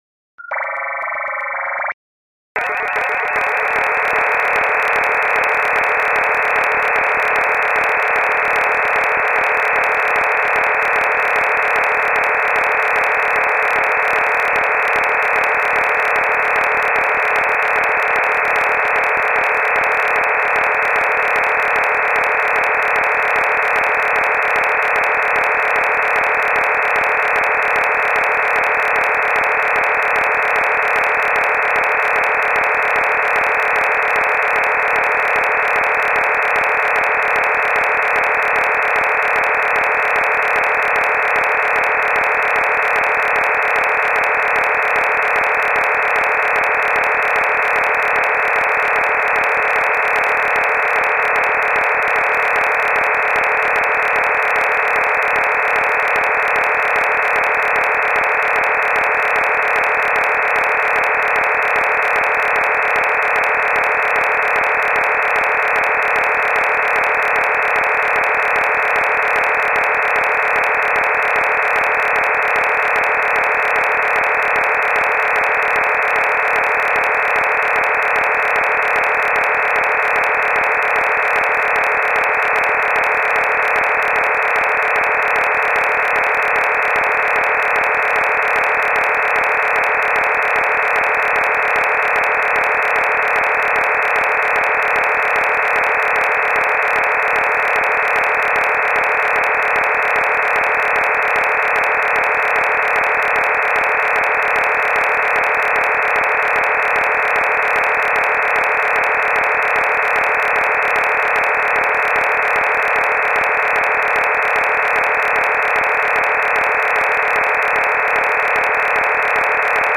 QSSTV_Binary_File_DRM-64QAM.mp3